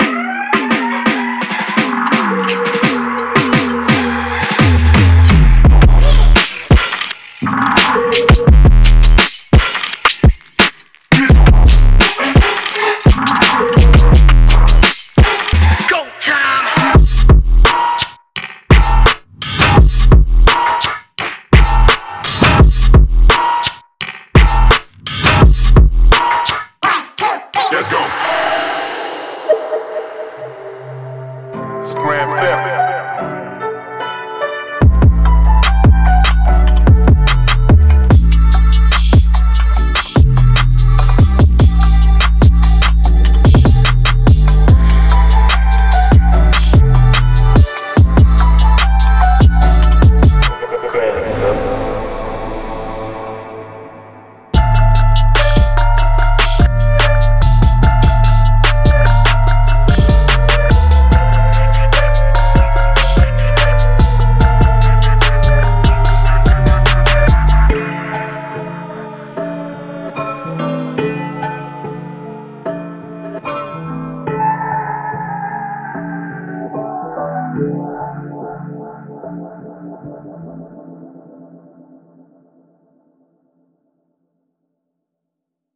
击脚，低调的高音帽，空灵的人声，经典的FX陷井，困扰的钟声，模糊的吉他，失真的钢琴以及
. 328个鼓循环和—拍
. 200个音调环